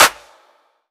Clap (40 oz).wav